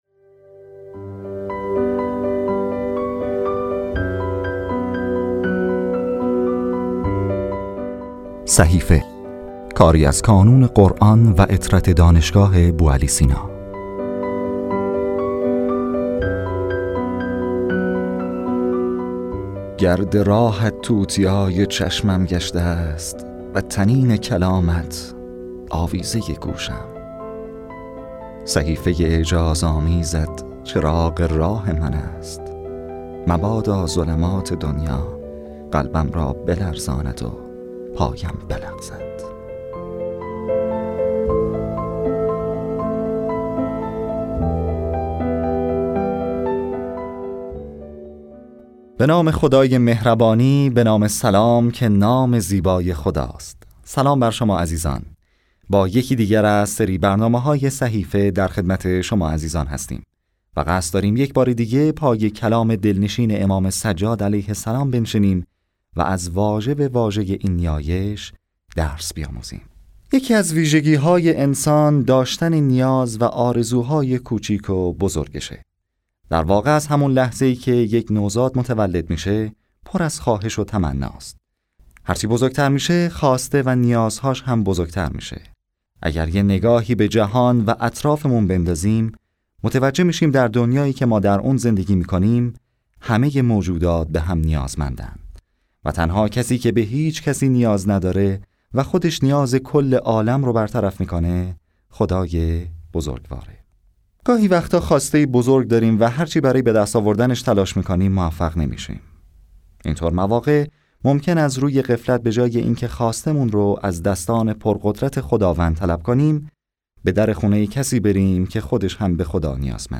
گروه چند رسانه‌ای ـ پادکست خوانش ترجمه صحیفه سجادیه با محوریت دعای سیزدهم منتشر شد.